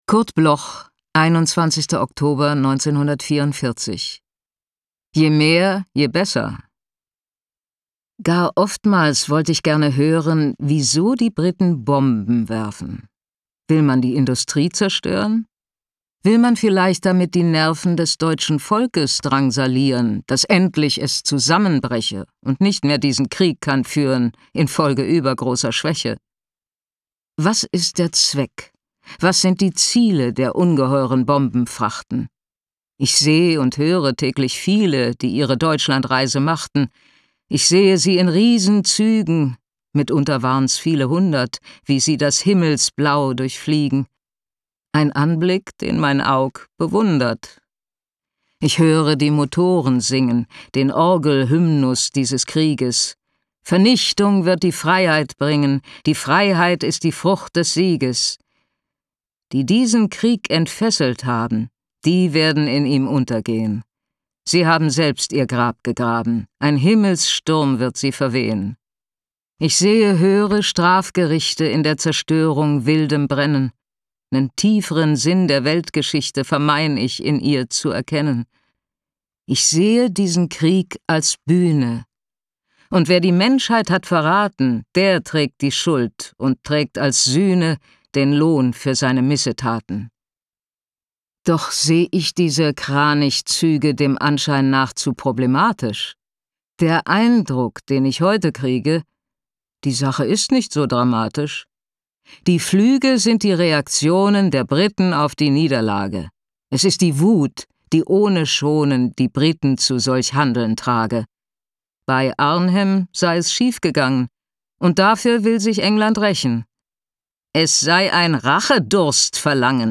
Recording: Argon Verlag AVE GmbH, Berlin · Editing: Kristen & Schmidt, Wiesbaden